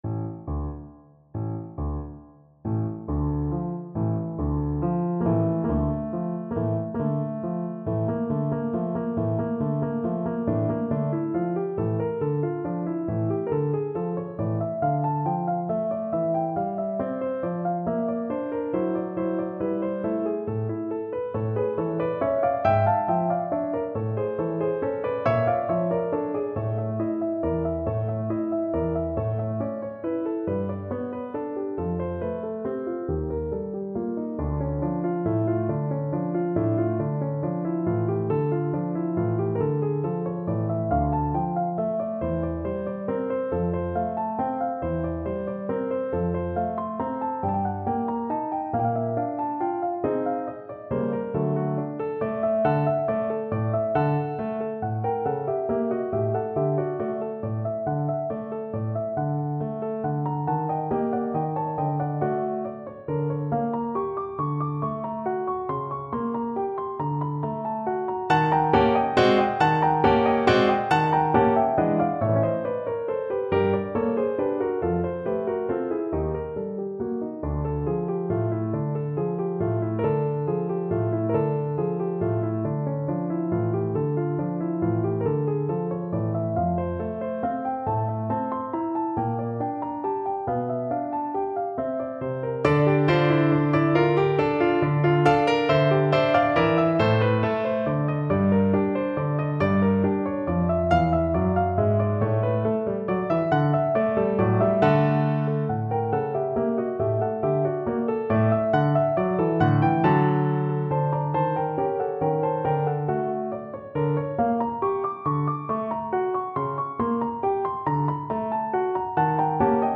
Play (or use space bar on your keyboard) Pause Music Playalong - Piano Accompaniment Playalong Band Accompaniment not yet available transpose reset tempo print settings full screen
Voice
3/8 (View more 3/8 Music)
D minor (Sounding Pitch) (View more D minor Music for Voice )
Behbig Mit Humor (Im Anfang =138)
Classical (View more Classical Voice Music)